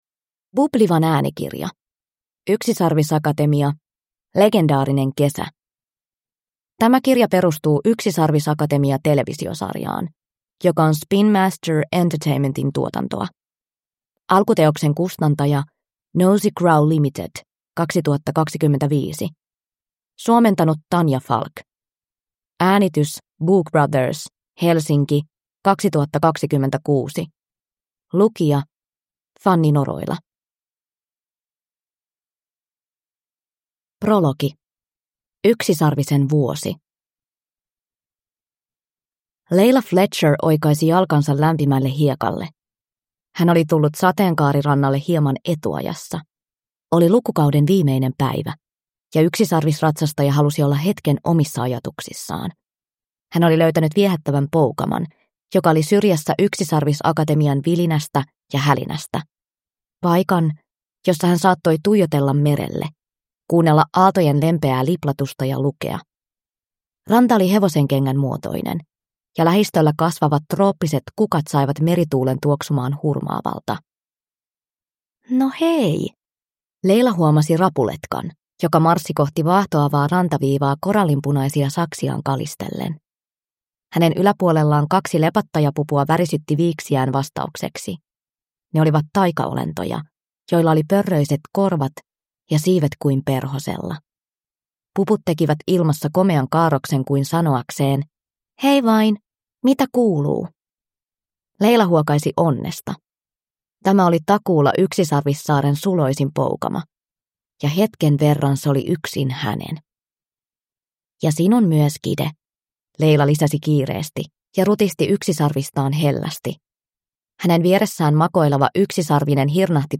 Yksisarvisakatemia - Legendaarinen kesä – Ljudbok